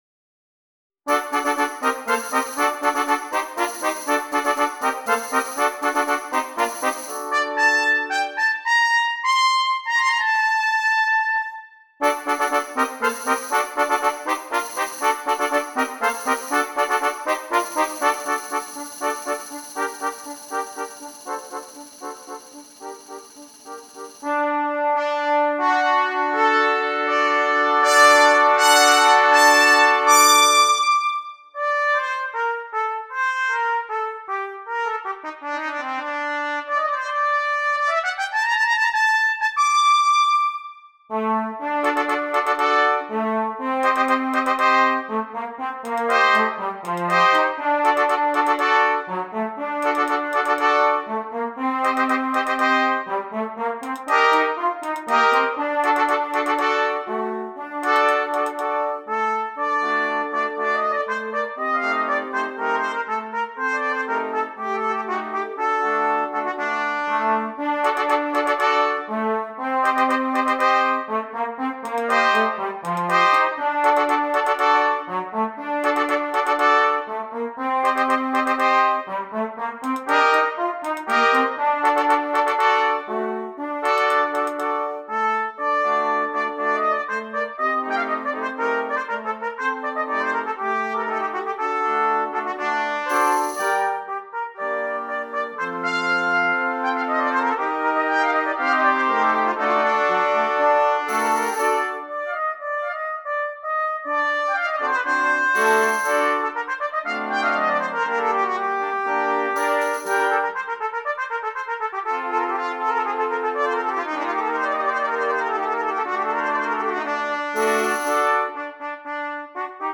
Brass Band
6 Trumpets
Traditional
a traditional bullfighting song